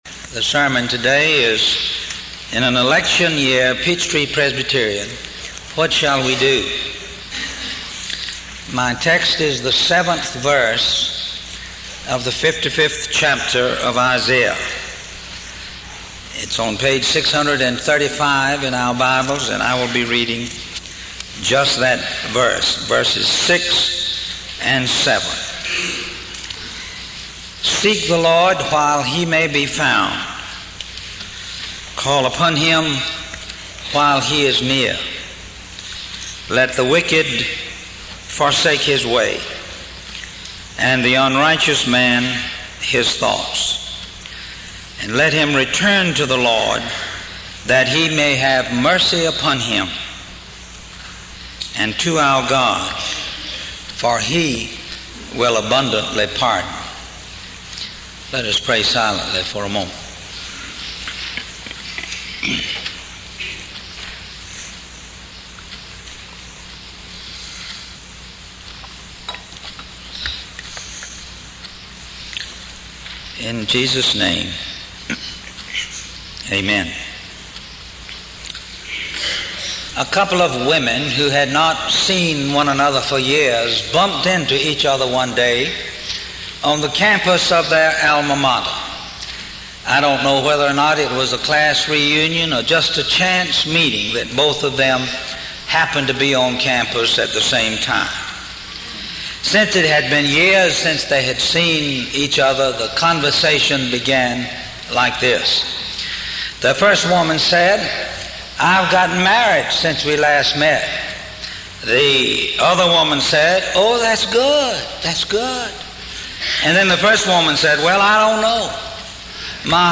7 Sermon Date